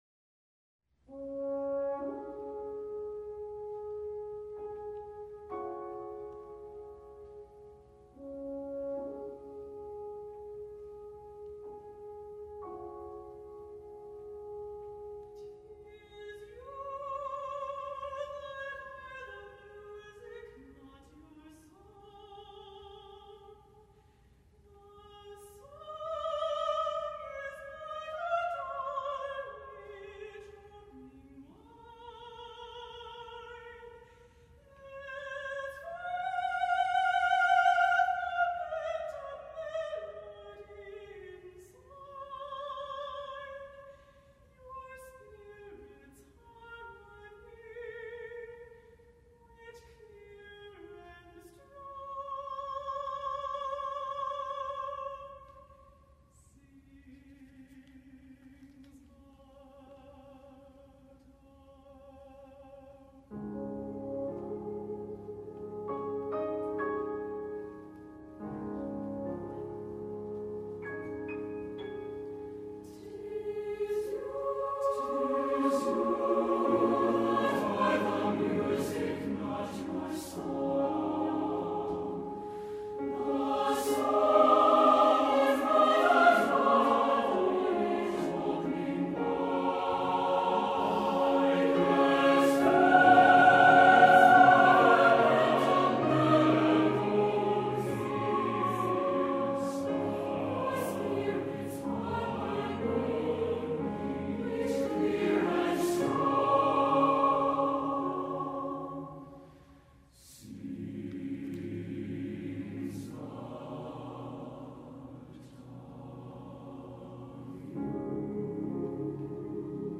Voicing: SSAATB, Piano and Horn